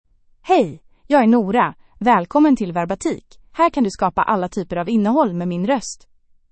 NoraFemale Swedish AI voice
Nora is a female AI voice for Swedish (Sweden).
Voice sample
Listen to Nora's female Swedish voice.
Nora delivers clear pronunciation with authentic Sweden Swedish intonation, making your content sound professionally produced.